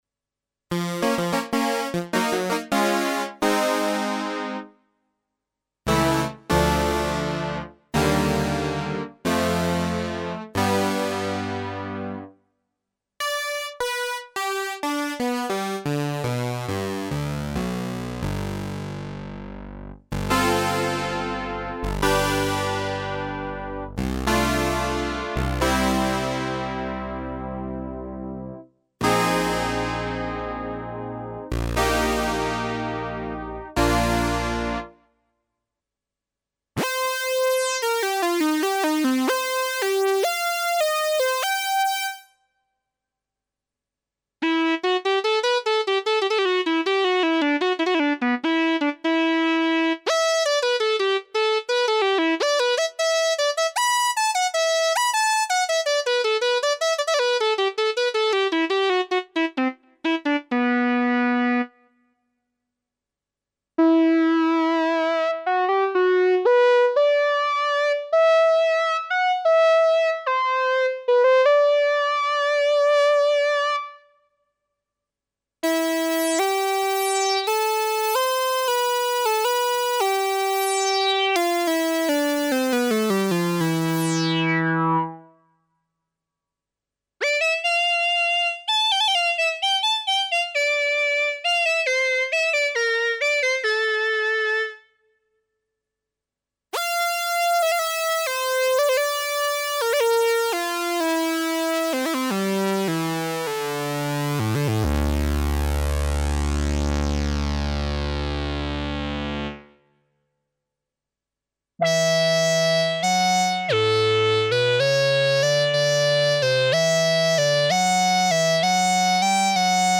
demo of some MMG2 patches.